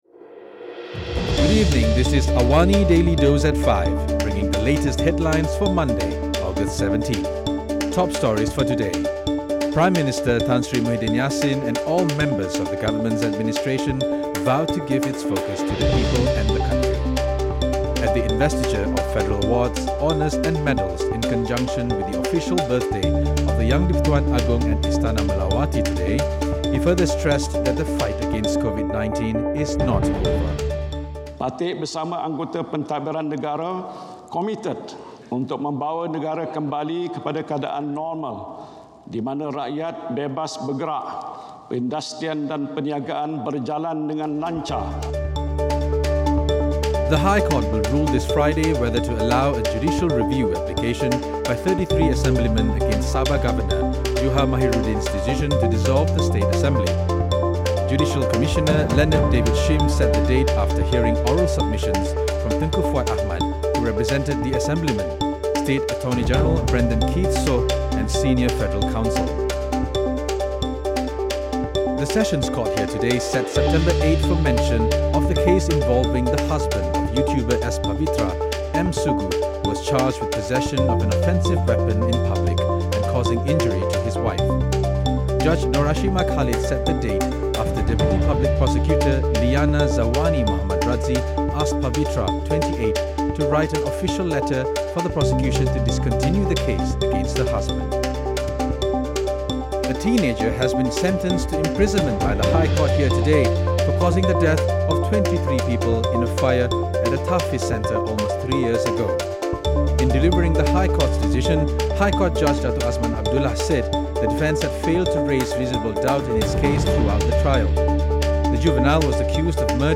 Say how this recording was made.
Listen to the top five stories of the day, reporting from Astro AWANI newsroom — all in 3 minutes.